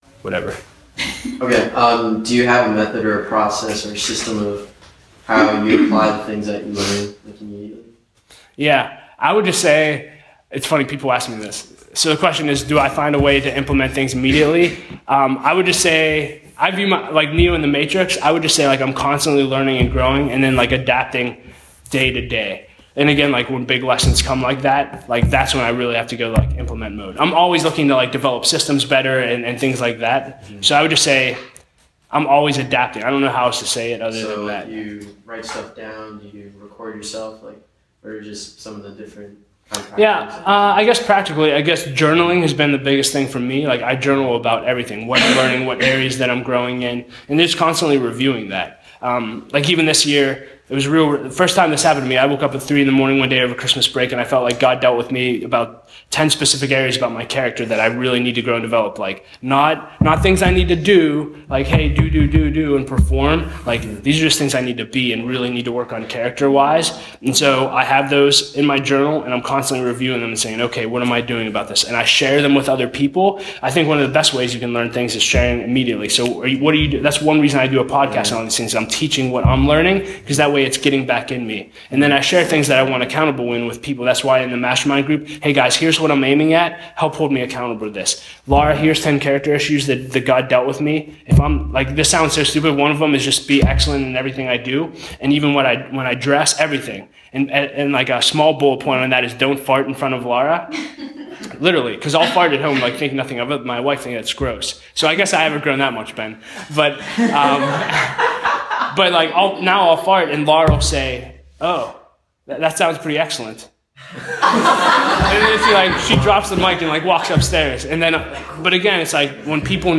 at the Next Level Leadership Academy